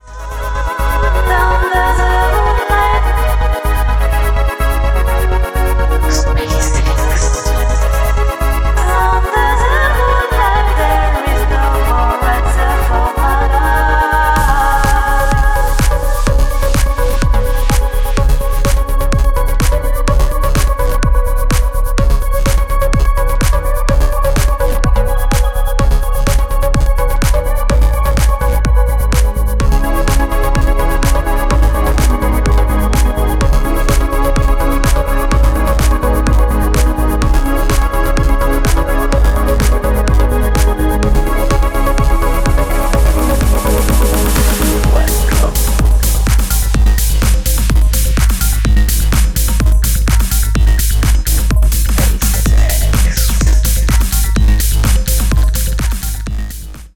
• Genre : indie dance, minimal techno, dark techno, house